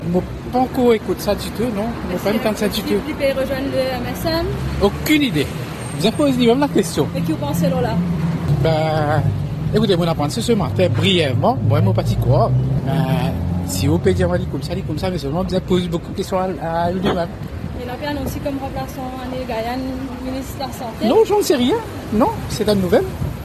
Paul Bérenger a annoncé que le député Zouberr Joomaye quittait le MMM. Quelques ministres ont commenté l’affaire à leur sortie du Conseil des ministres, vendredi 23 septembre.